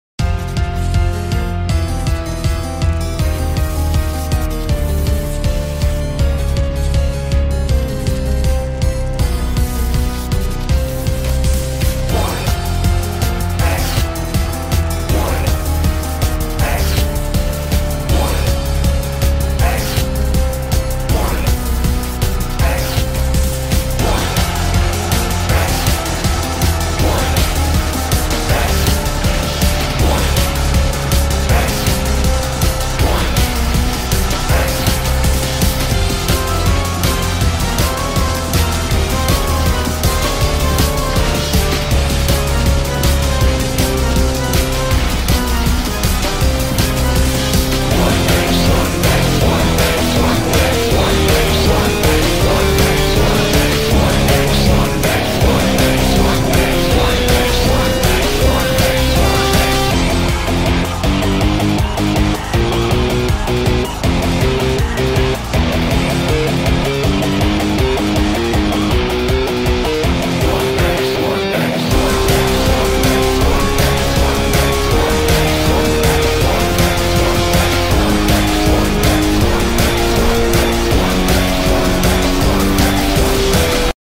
Not exactly the same generator sound effects free download